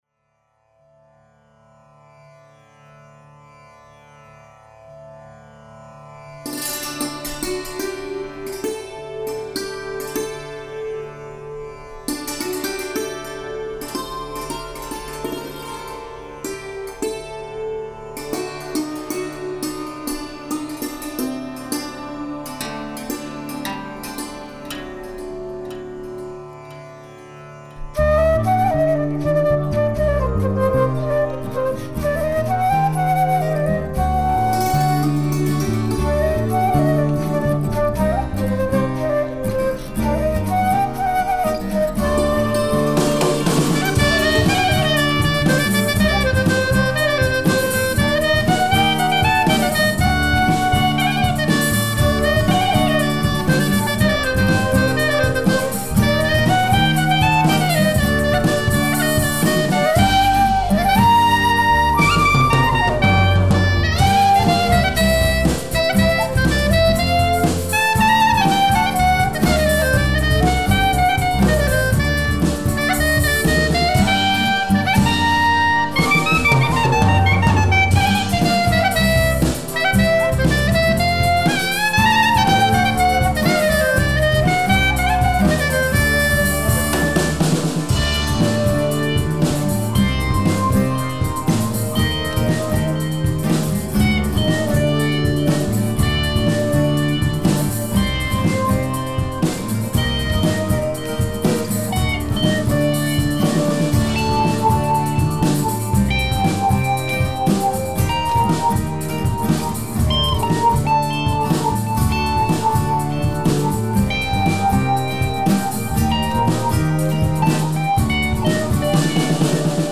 קלרינט
גיטרה חשמלית
גיטרה אקוסטית
חליל  צד
פסנתר ואורגן ועוד...